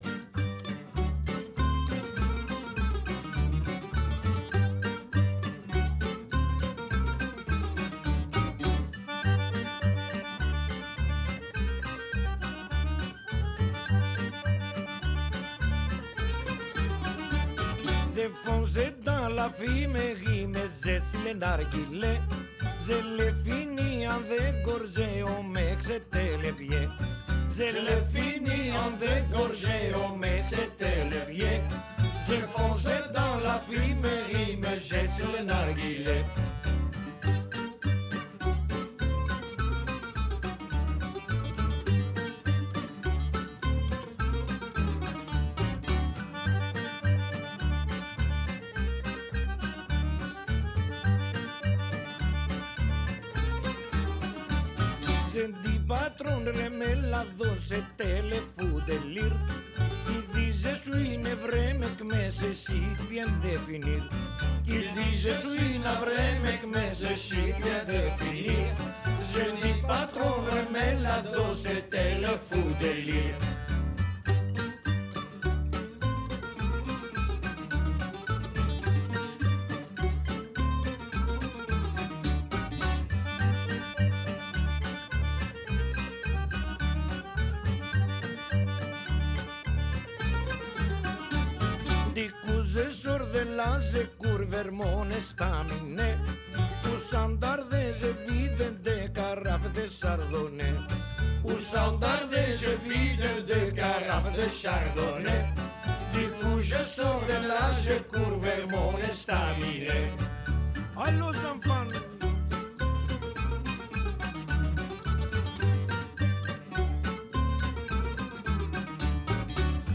Η ρεμπέτικη κομπανία των Βρυξελλών “Kosmokratores” στη “Φωνή της Ελλάδας”